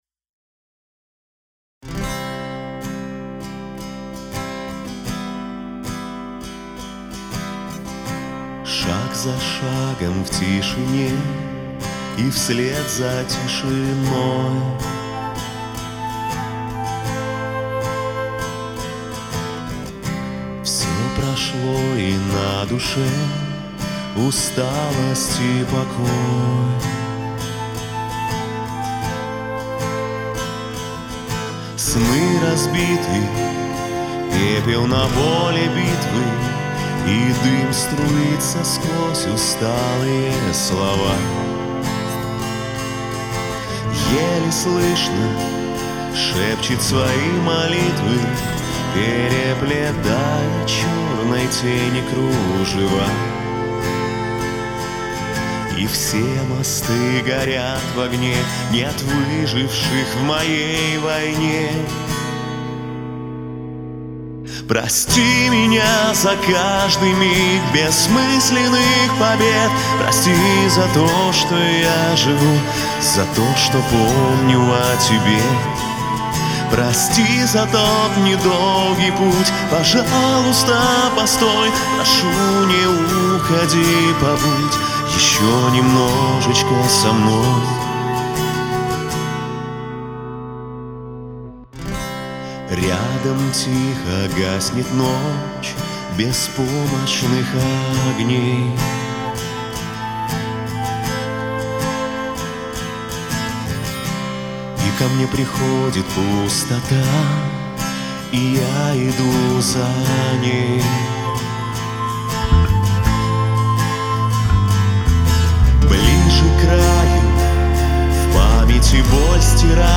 Мужской
Бас Баритон